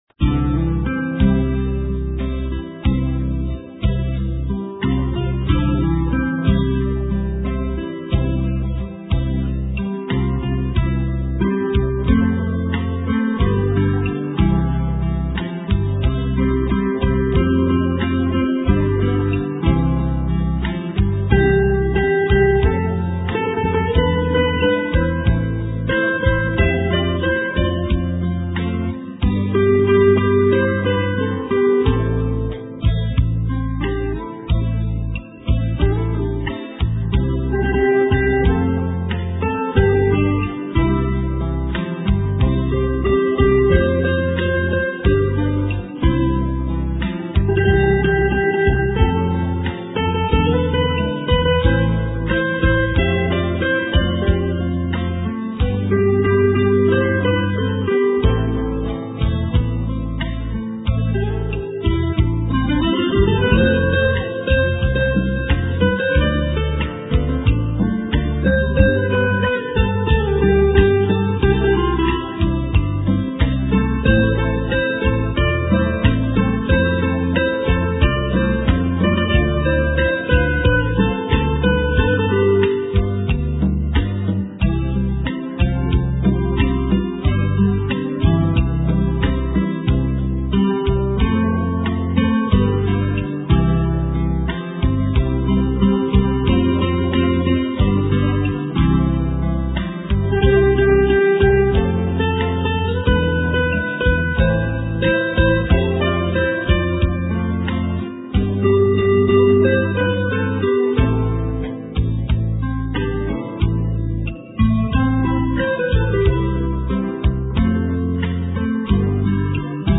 * Thể loại: Ngoại Quốc